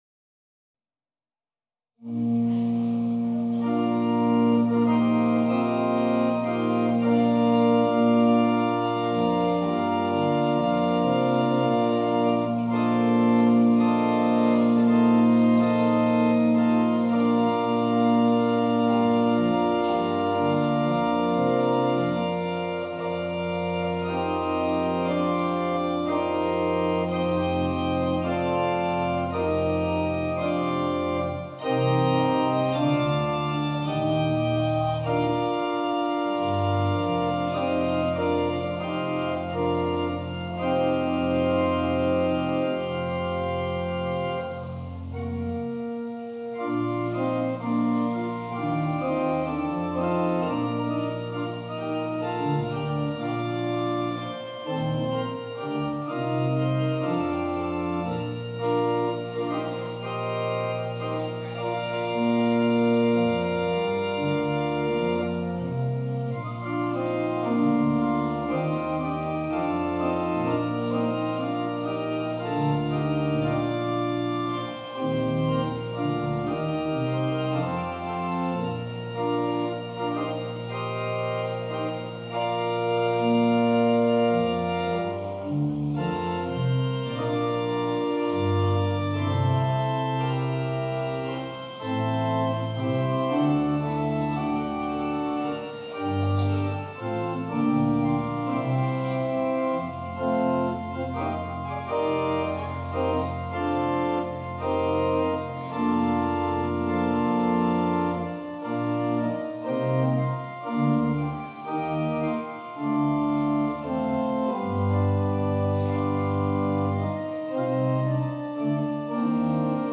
Passage: Luke 19: 28-40 Service Type: Holy Day Service Scriptures and sermon from St. John’s Presbyterian Church on Sunday